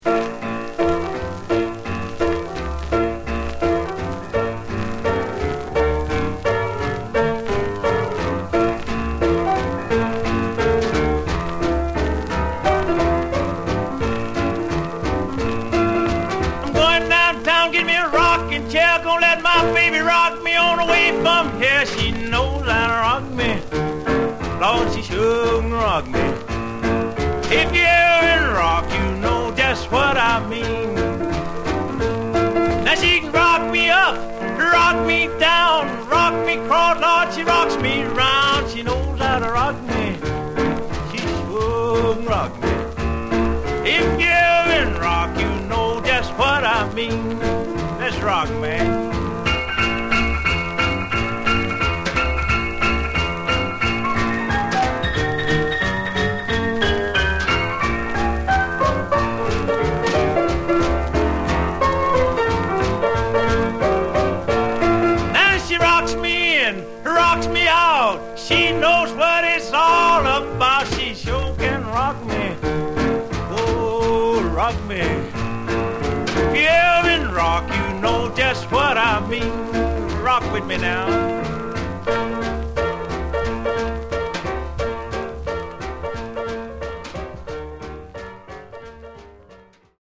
Genre: Rockin' RnB